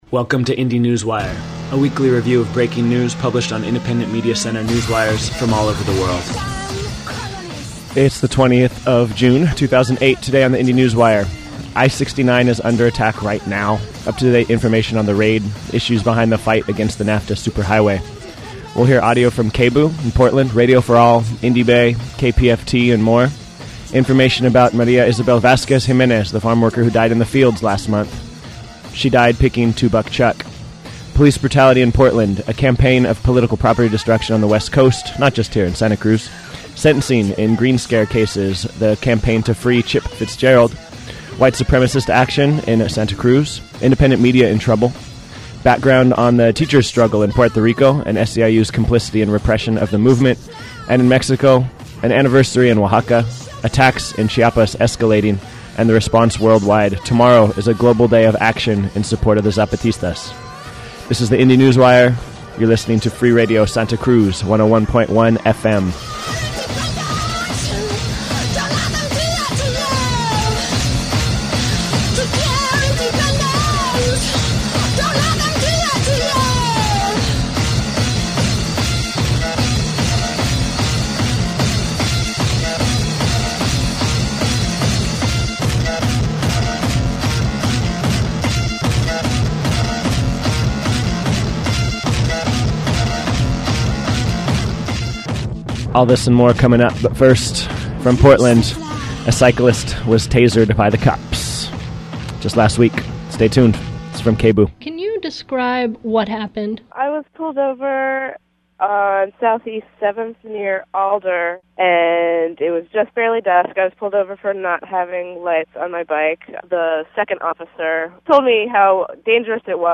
This news show airs weekly on Freak Radio, Friday mornings 10-12 noon, broadcasting news and opinion from independent media worldwide, focused on indymedia sites but also drawing from other websites.